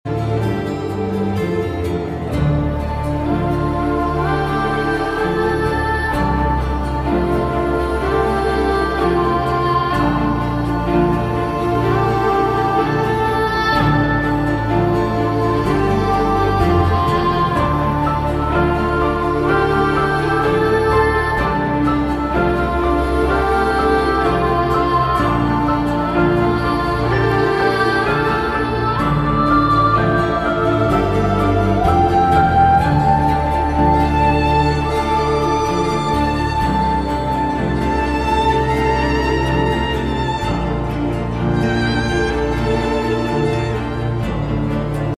Best Video Game Soundtracks